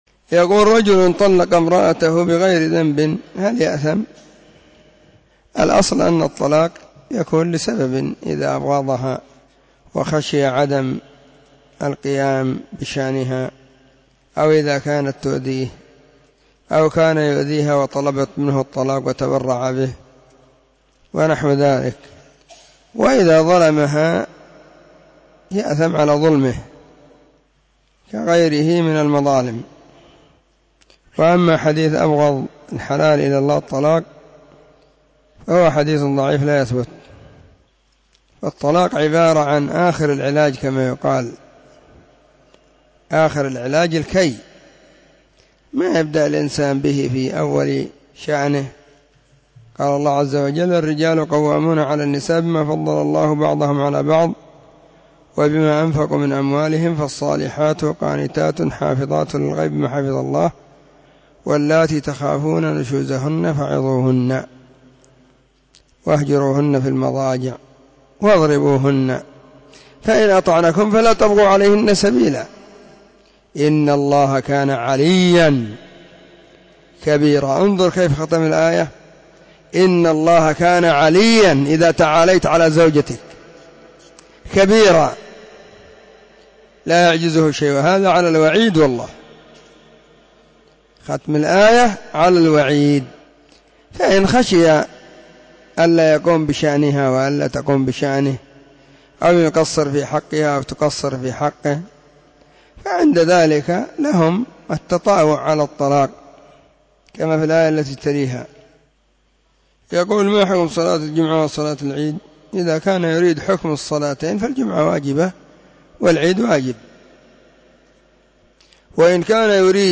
سلسلة الفتاوى الصوتية